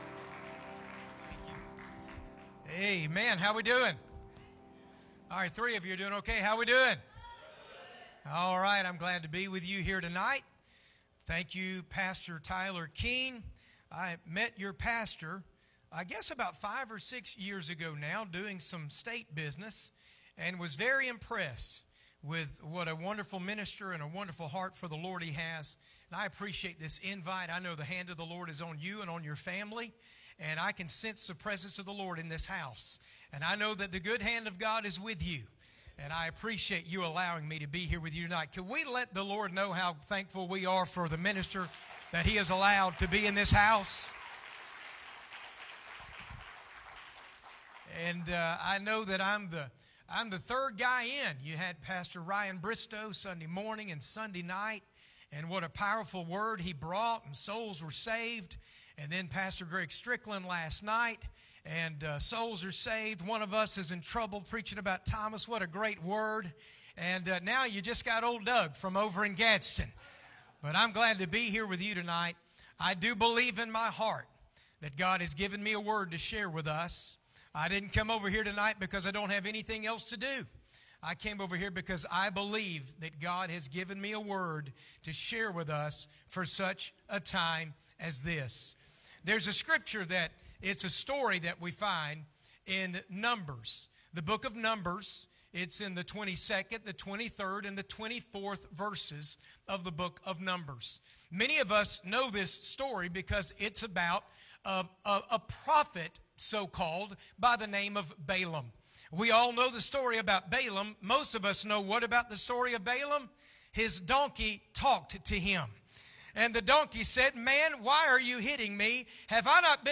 Guest Speaker
Tuesday PM Service